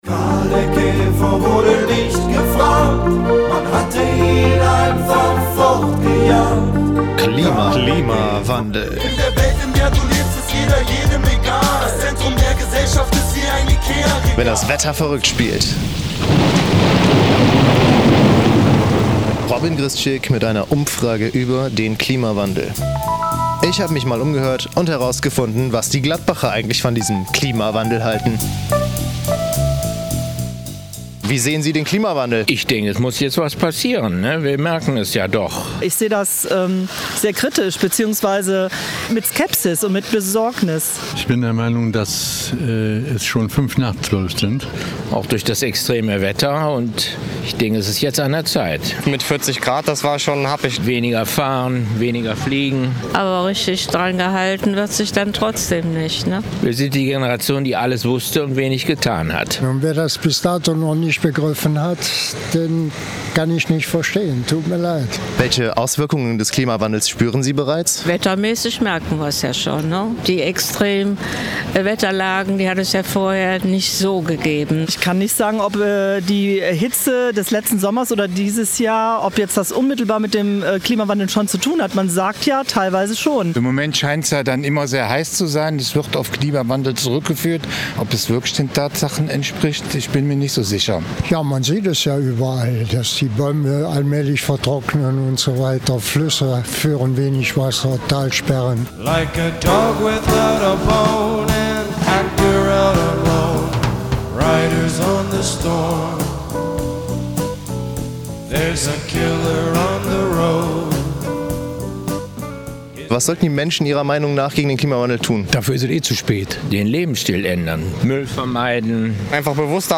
Umfrage-Klimawandel-Komplett-RG.mp3